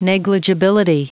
Prononciation du mot negligibility en anglais (fichier audio)
negligibility.wav